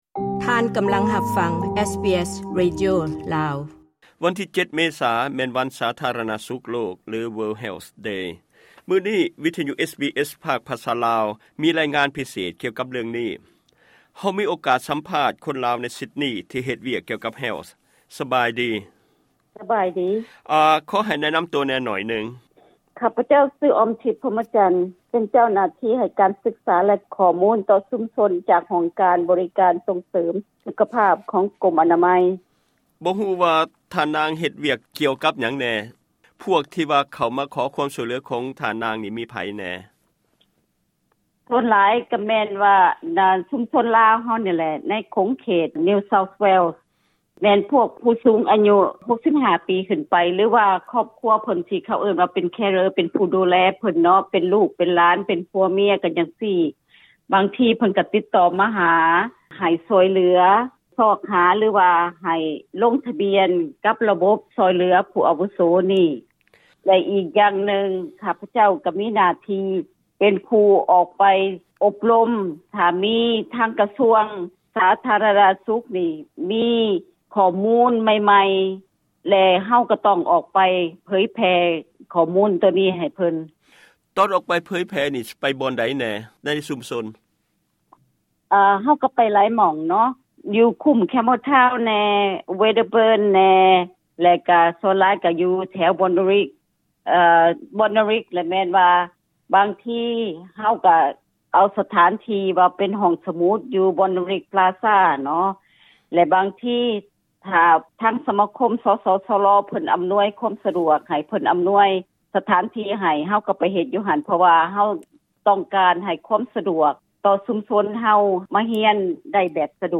ສຳພາດກ່ຽວກັບການດູແລຜູ້ສູງອາຍຸ